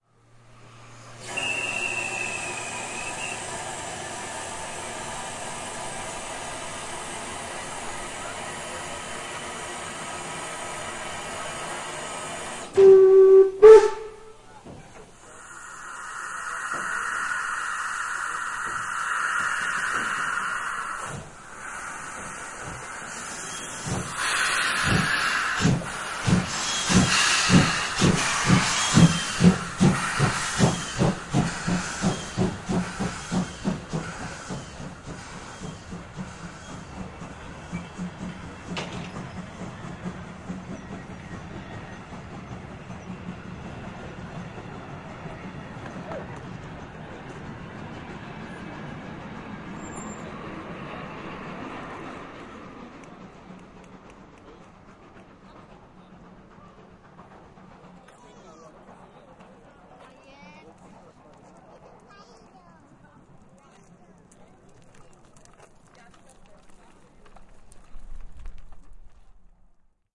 技术声音 " 蒸汽火车（合成）03
描述：Sony IC Recorder录制了原始曲目，并且已经通过此效果在Audacity中进行了编辑：Paulstretch，Tremolo和Change tempo / pitch。
标签： 机车 铁路 蒸汽火车 历史 历史 机车 铁路路 汽机车 铁路 公路
声道立体声